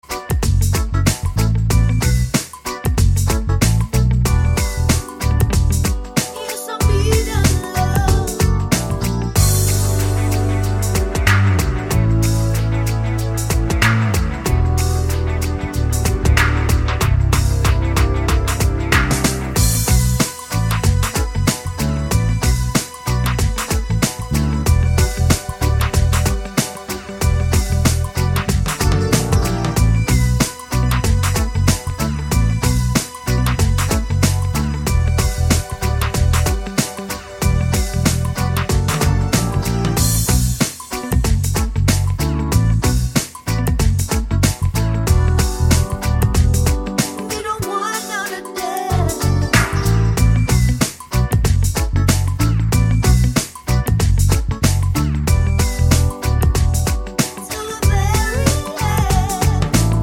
no Backing Vocals Reggae 4:22 Buy £1.50